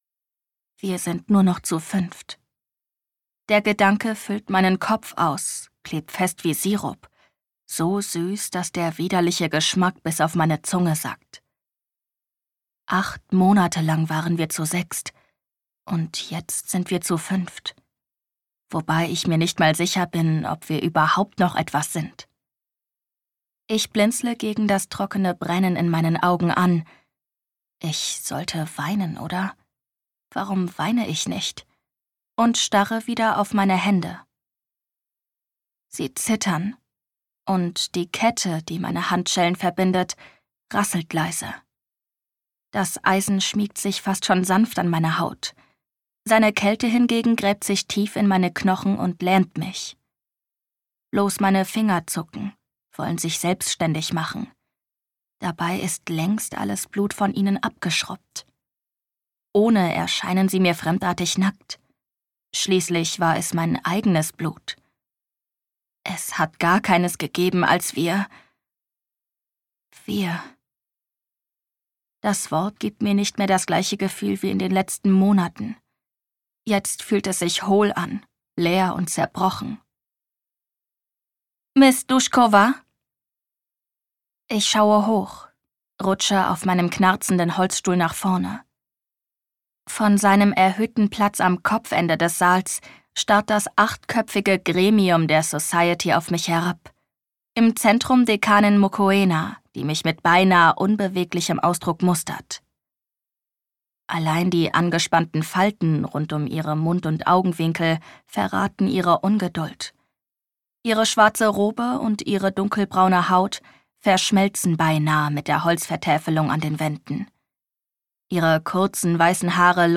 Hörbuch Print